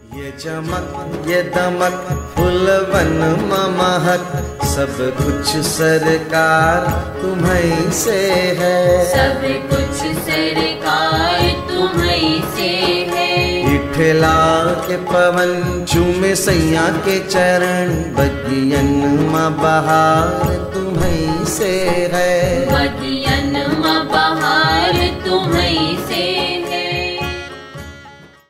Bhakti Ringtones